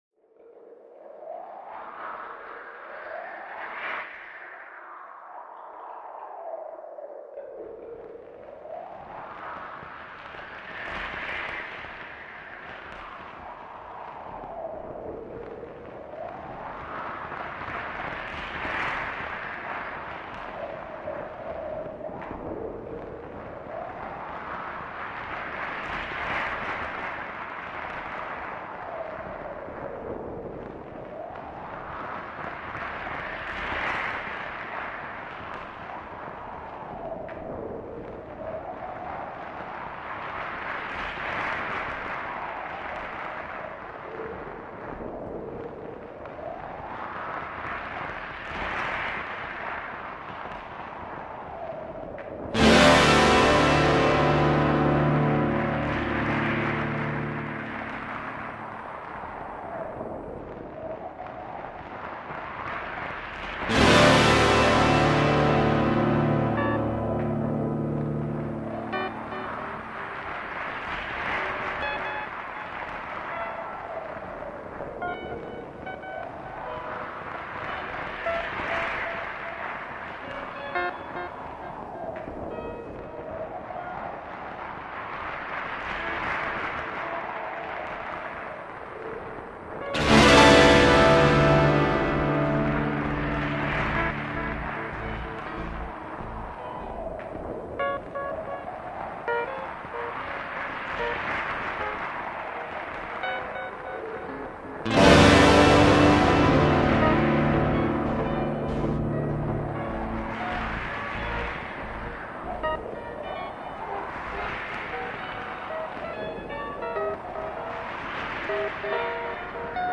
6/4, noire = 95.
On prendra plus le temps de les faire sonner que sur cet enregistrement de travail.
[0'00] les scratchs sur un cycle de 6 temps
[0'52] avec les accords
[1'06] puis le groupe en charge de la pluie rentre progressivement
[1'45] 1er "tiré", les scratchs passent dans un cycle de 3 temps
[2'41] le dernier accord avec l'entrée des e-bow
[3'35] entrée du "tapping bestioles" et "tangués"
[4'18] cette version se termine par un accord, pas celle que nous jouerons...
L’idée est de donner une sensation de bruit de mer.